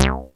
ACIDBAS2.wav